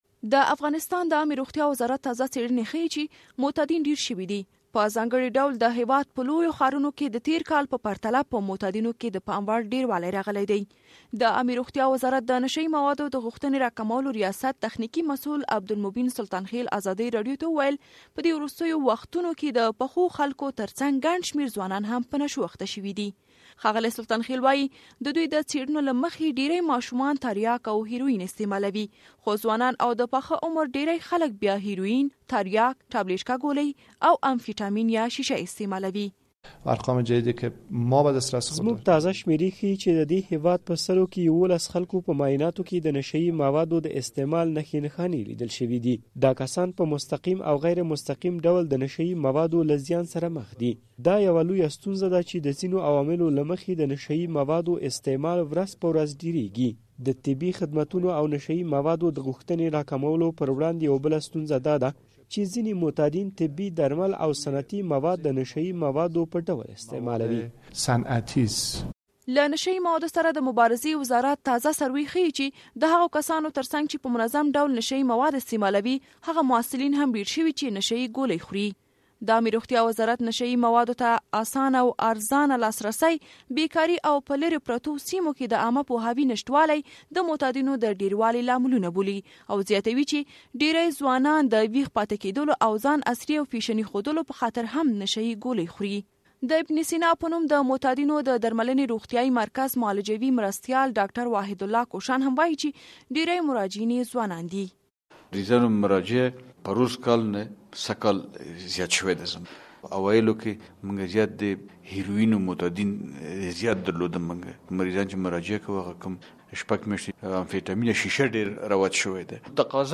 د زهرو کاروان فیچر
فیچر